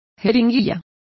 Complete with pronunciation of the translation of syringe.